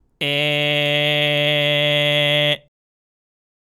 ※喉頭は小人の状態でグーで口